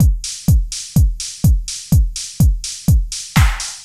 Index of /musicradar/retro-house-samples/Drum Loops
Beat 13 Full (125BPM).wav